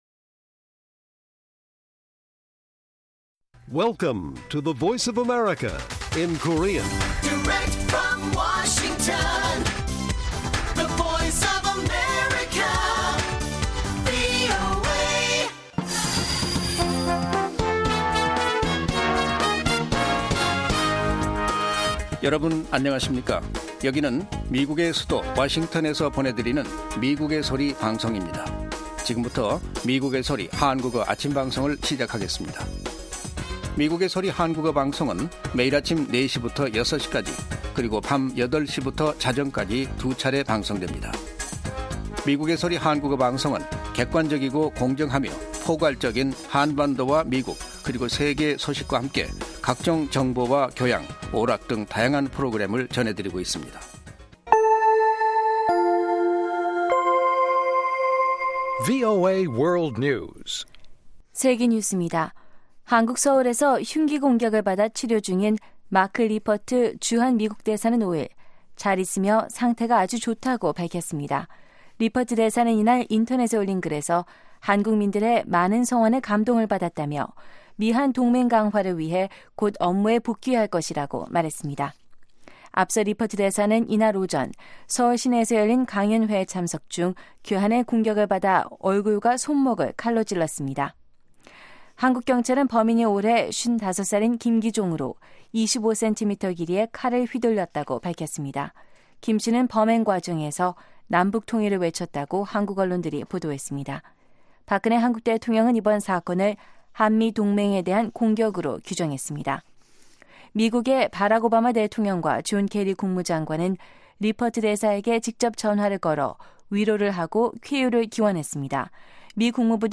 VOA 한국어 방송의 아침 뉴스 프로그램 입니다. 한반도 시간 매일 오전 4시부터 5시까지 방송됩니다.